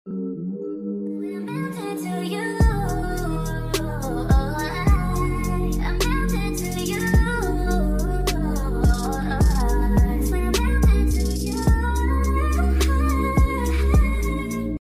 Stop motion sounds for uuu🌟🌟💕 sound effects free download